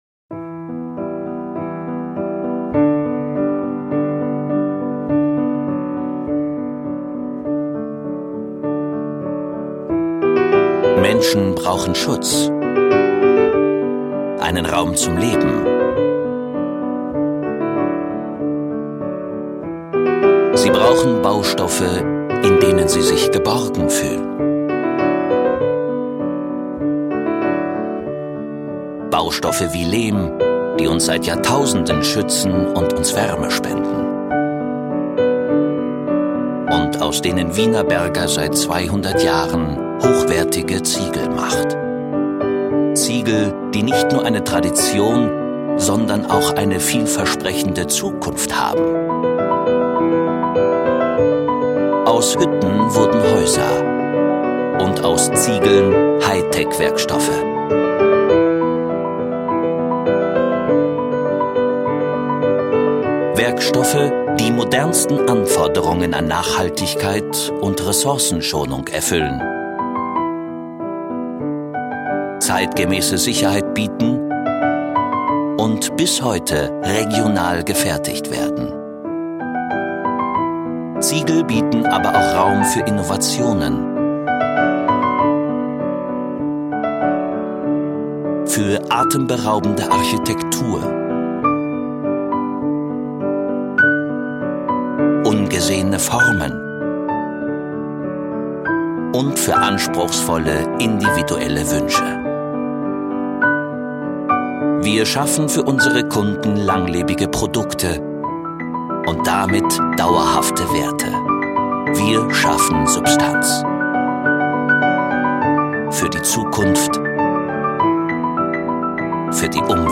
Sprechprobe: Industrie (Muttersprache):